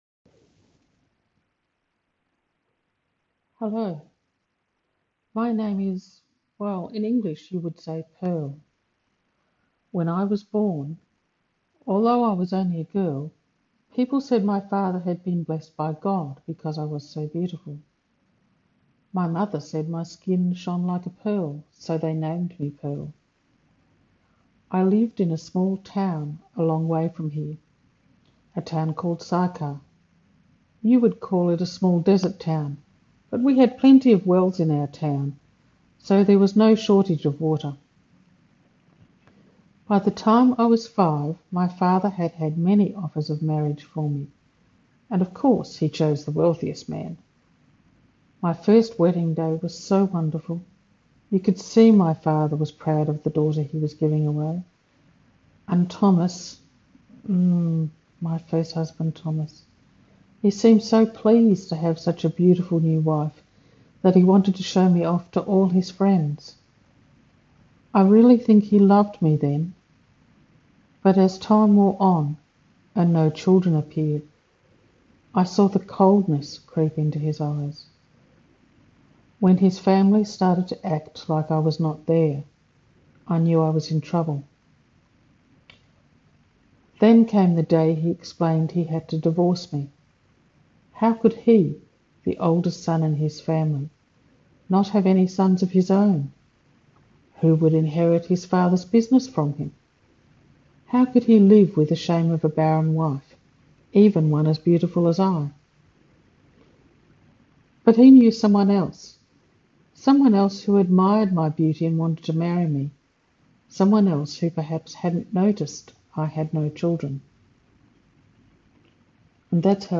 A short one person play based on John 4:5-42, the story of the Samaritan woman at the well. It gives the story from her perspective filling in (from my imagination) the reasons for her state.